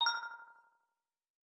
Echo Metallic Alert.wav